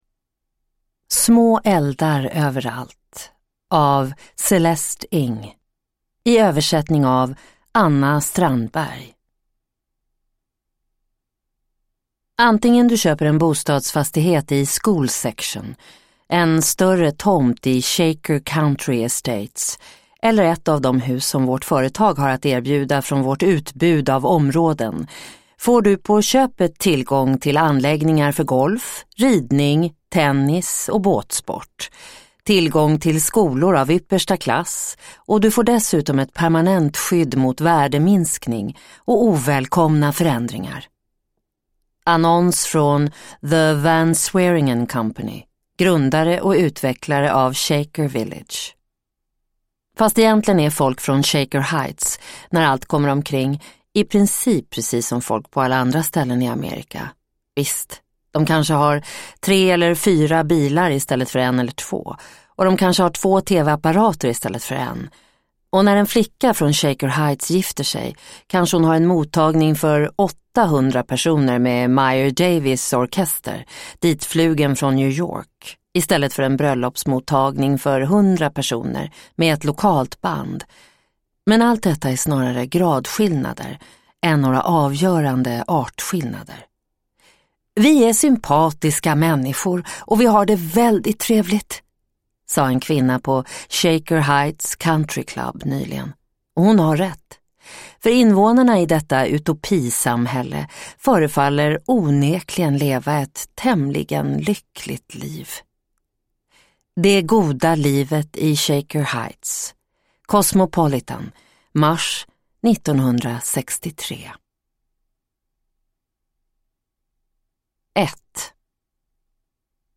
Små eldar överallt – Ljudbok